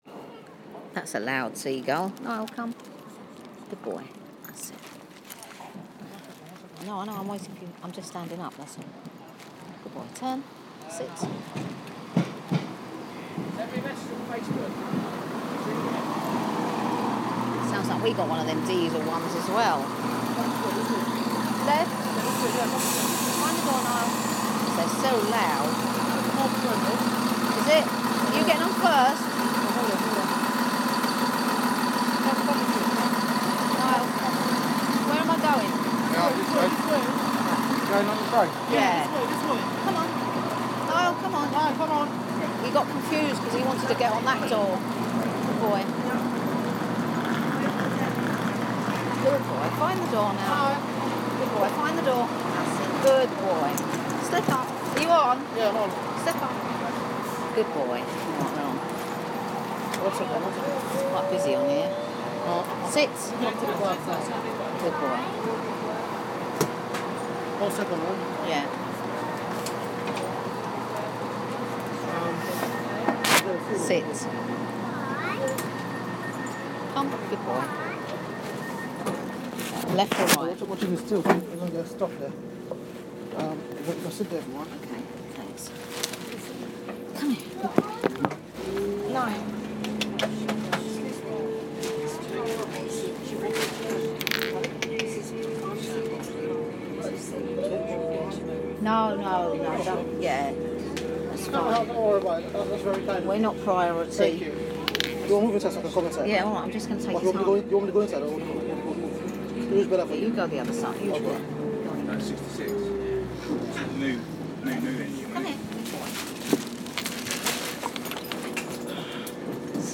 On the train to Rye